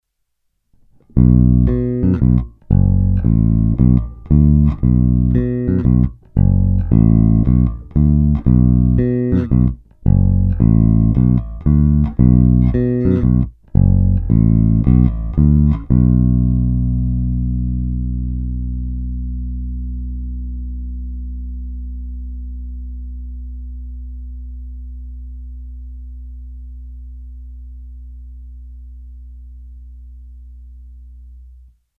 Není-li uvedeno jinak, následující nahrávky jsou vyvedeny rovnou do zvukové karty a kromě normalizace ponechány bez zásahů. Tónová clona byla vždy plně otevřená.
Oba snímače paralelně